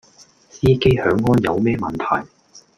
Голоса - Гонконгский 430